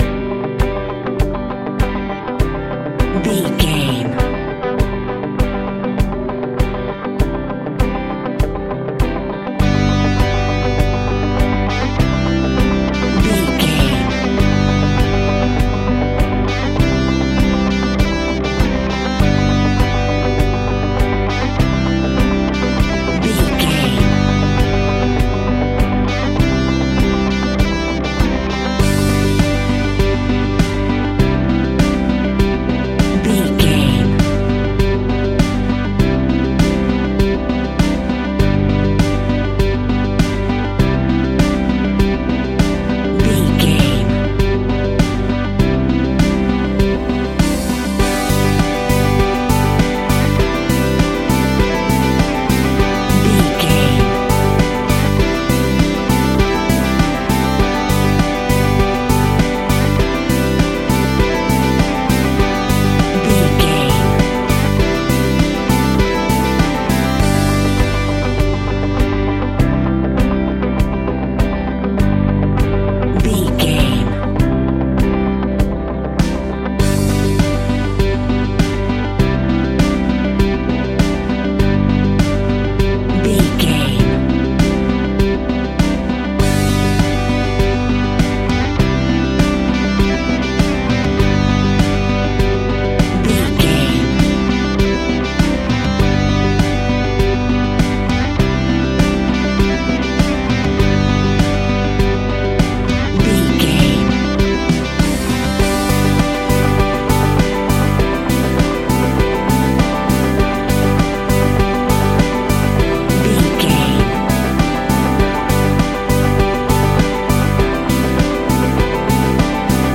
Ionian/Major
pop rock
indie pop
fun
energetic
uplifting
instrumentals
upbeat
groovy
guitars
bass
drums
piano
organ